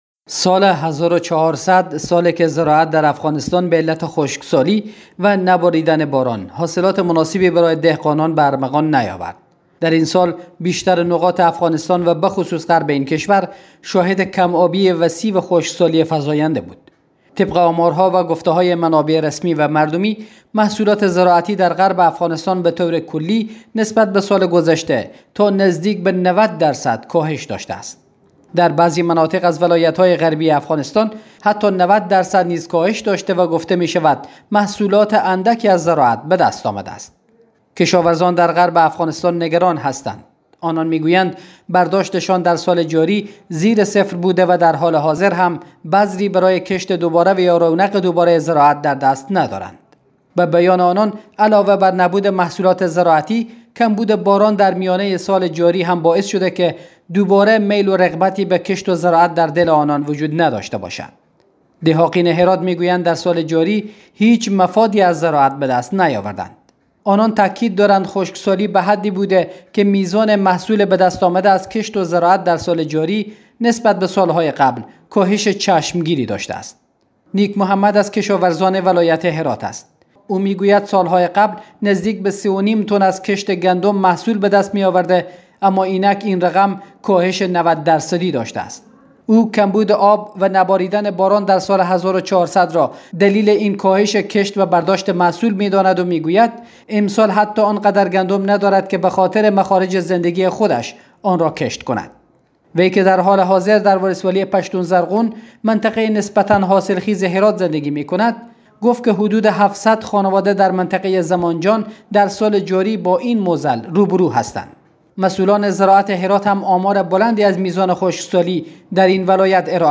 گزارش تکمیلی از خبرنگار رادیو دری هشدار درباره گسترش فقر در افغانستان کلیدواژه افغانستان اقتصادی اقتصاد خشکسالی مطالب مرتبط باران‌ امید در هرات؛ آیا سایه خشکسالی کنار می‌رود؟